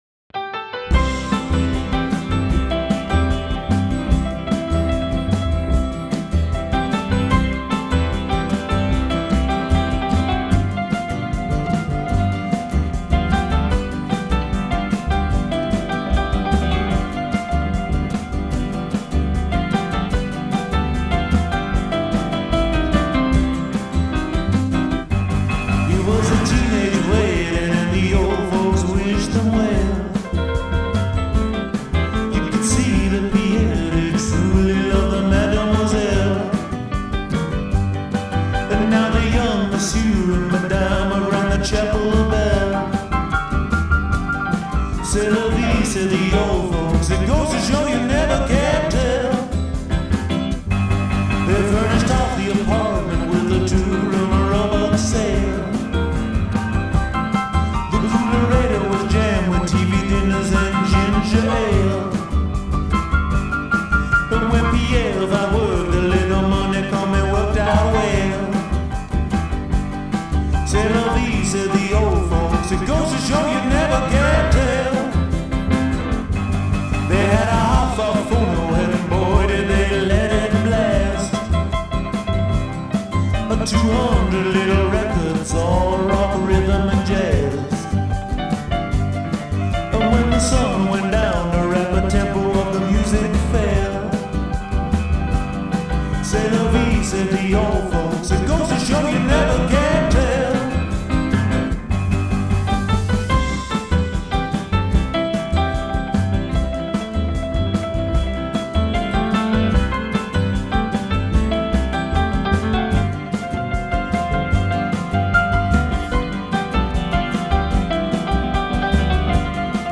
50s Rock and Roll band Hire Melbourne